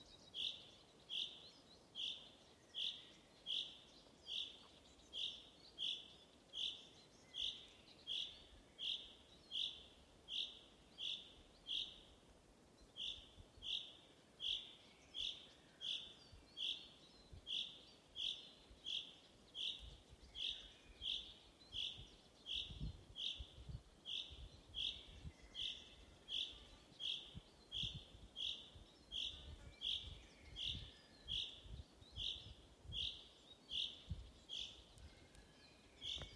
Birds -> Finches ->
Chaffinch, Fringilla coelebs
StatusAgitated behaviour or anxiety calls from adults